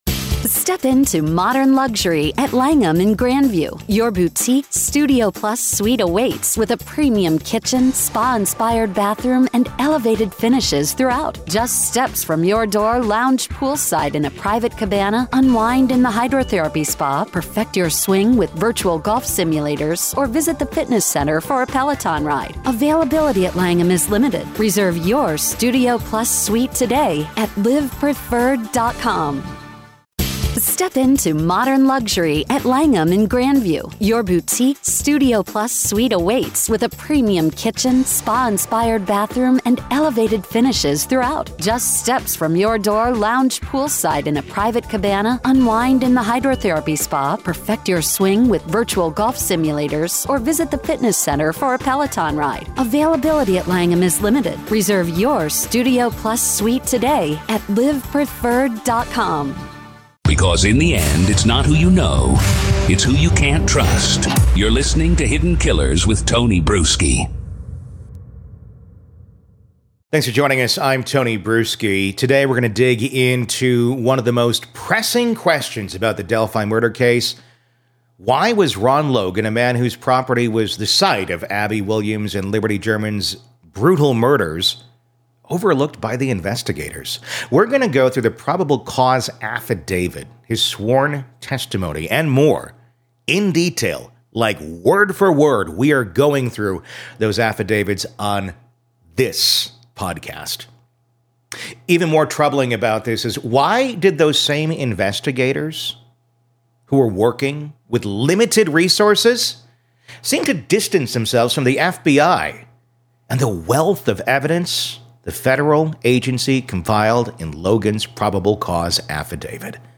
Join us as we read the affidavit in its entirety and confront the hard questions about this baffling case.